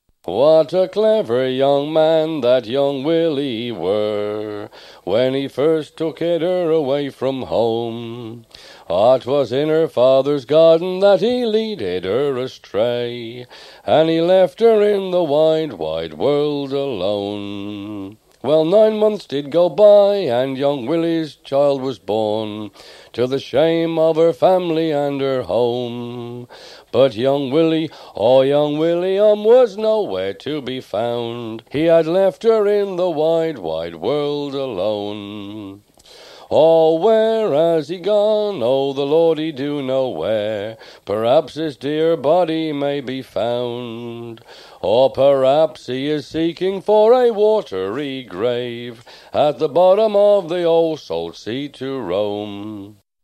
He's an English Gypsy, around 60 years of age at the time of recording, yet has none of the country and western influences one so often hears from younger generations of Gypsies these days.  He's a very good singer, and this is a great song I'd never heard before, which uses the same tune as the Brazil Family of Gloucester use for the very rare My Schoolmaster's Son.
Given that these were all field recordings with domestic recorders, the sound quality is as good as you could expect, and allows a perfectly pleasant listening experience.